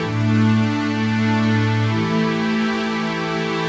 Index of /musicradar/80s-heat-samples/130bpm
AM_80sOrch_130-A.wav